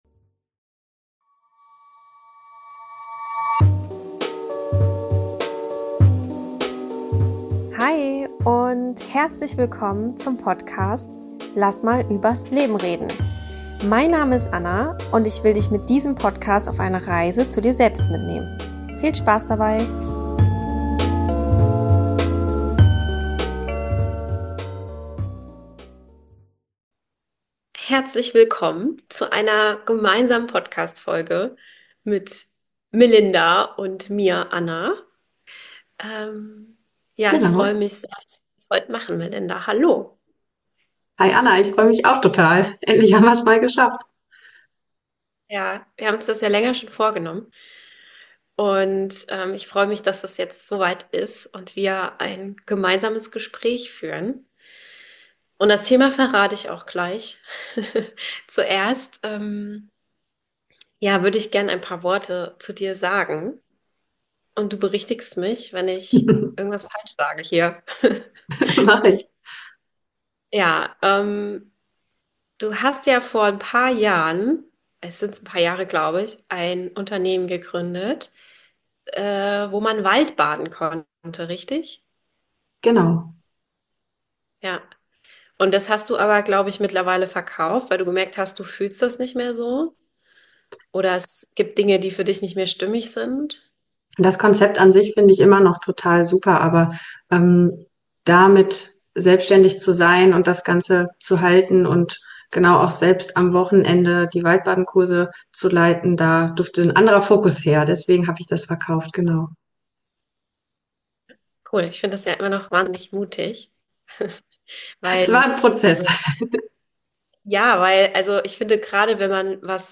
17: Wie kann ich authentisch sein und leben? Ein Interview